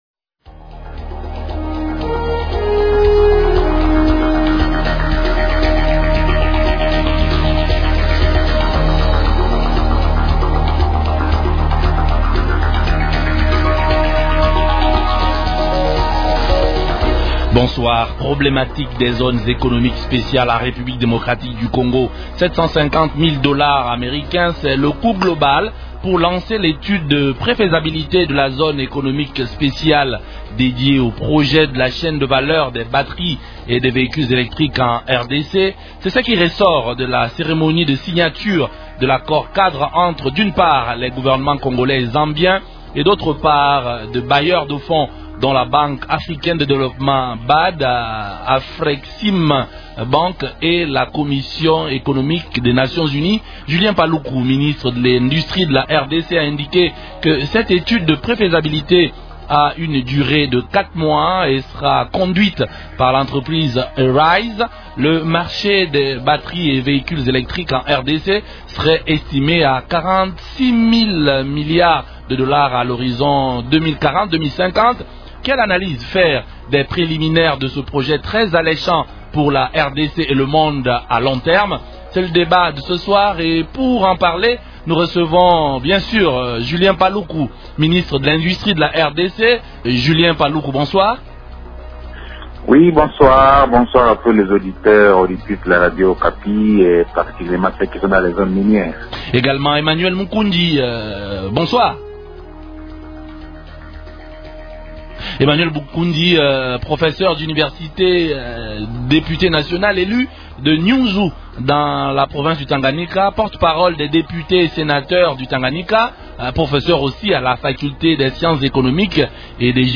L'actualité politique de ce soir
Question : -Quelle analyse faire des préliminaires de ce projet très alléchant pour la RDC à long terme ? Invités : -Julien Paluku, ministre de l’Industrie du gouvernement central.